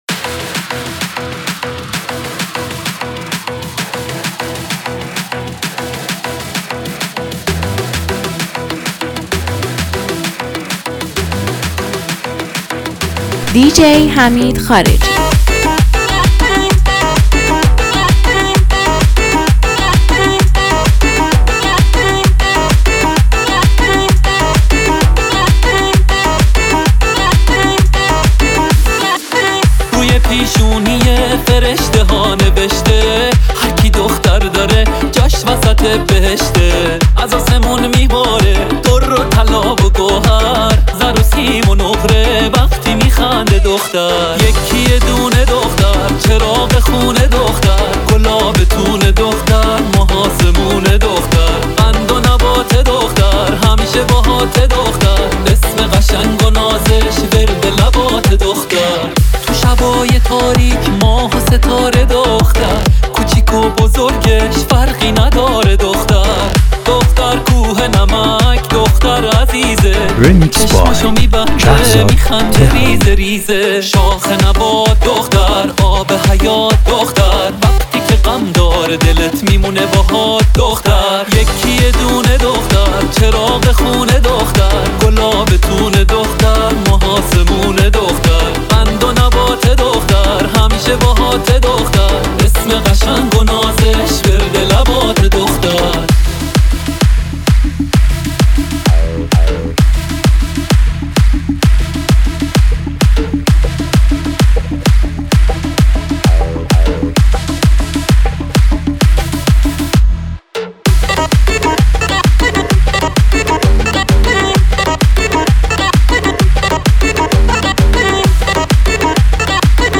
این ریمیکس جذاب، برای دورهمی‌ها و لحظات شاد شما آماده شده.